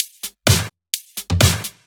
Index of /VEE/VEE Electro Loops 128 BPM
VEE Electro Loop 278.wav